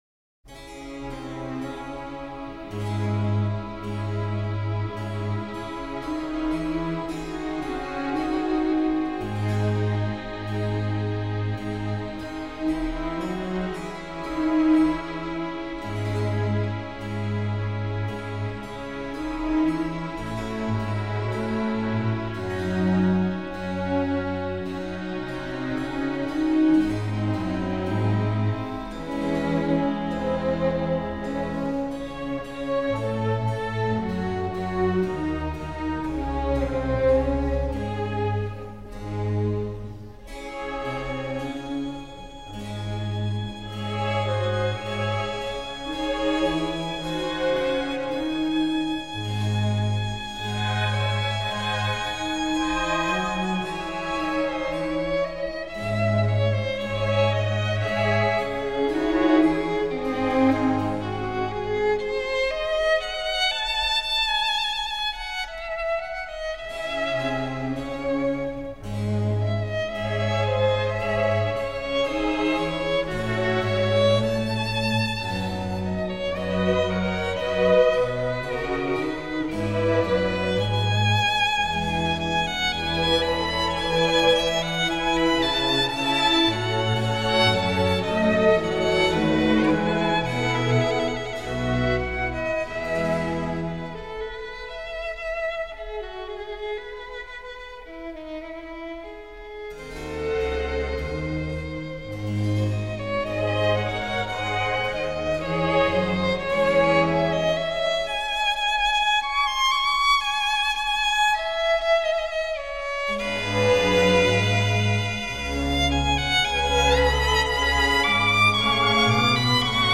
C01-08 Bach ViolinConcerto in E BWV 1042 Adagio | Miles Christi